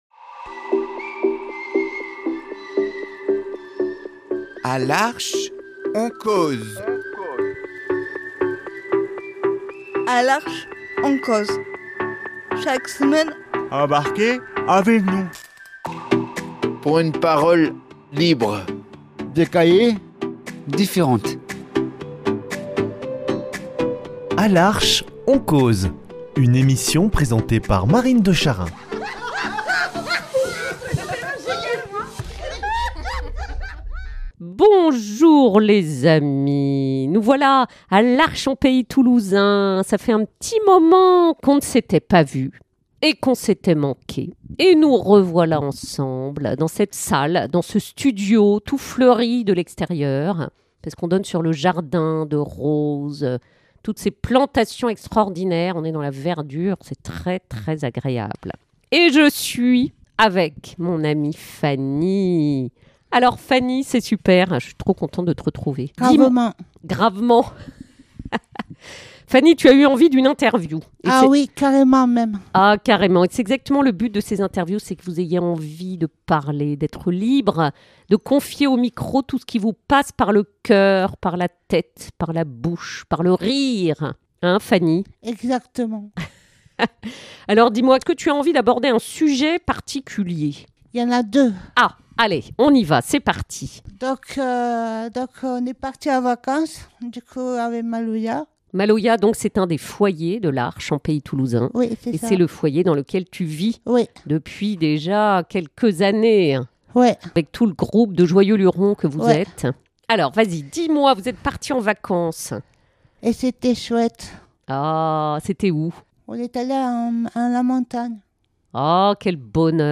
Elle nous raconte, encore émue d’admiration pour ces rapaces, prête à organiser un hold-up en cagoules pour adopter la chouette Chaussette !